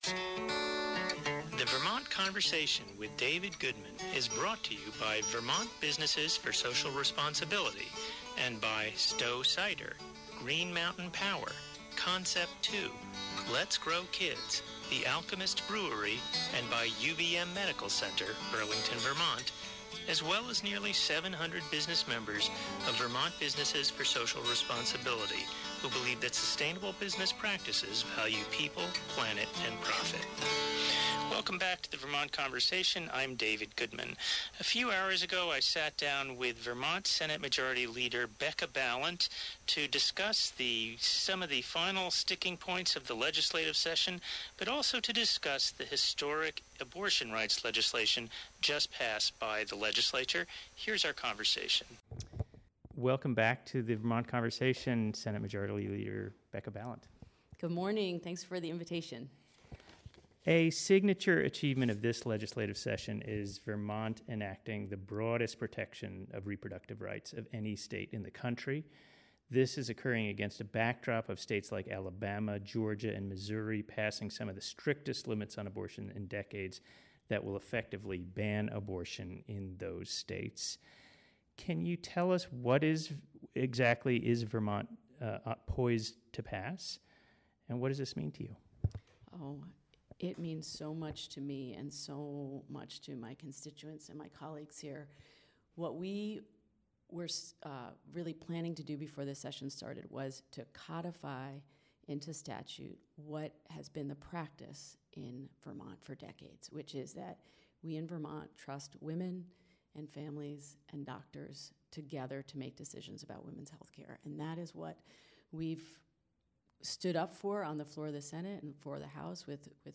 Includes longer version of interview than aired on WDEV.